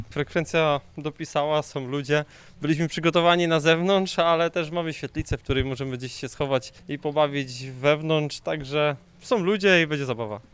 W czwartek (17 lipca) wydarzenie miało dziać się na dworze, jednak pogoda pokrzyżowała plany i trzeba było przenieść potańcówkę pod dach, o czym mówi Fabian Stachowicz, sołtys Siedlca.